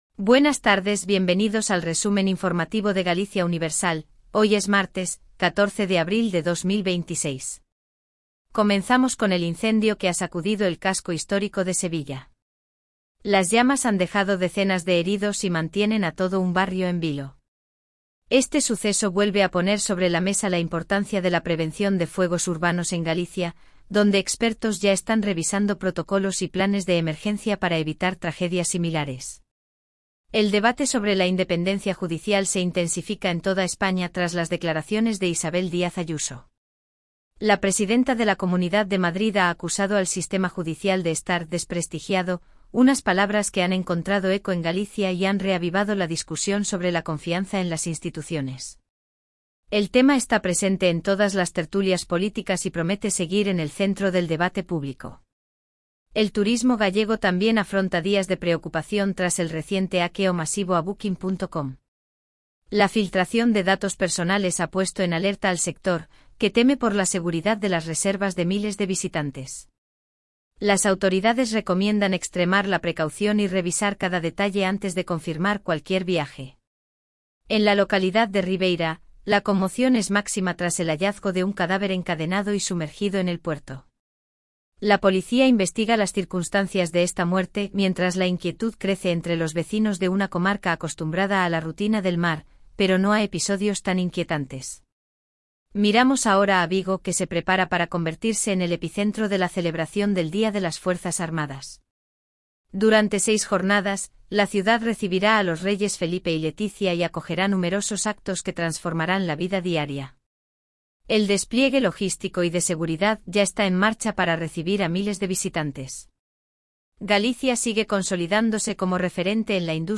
🎙 PODCAST DIARIO
Resumen informativo de Galicia Universal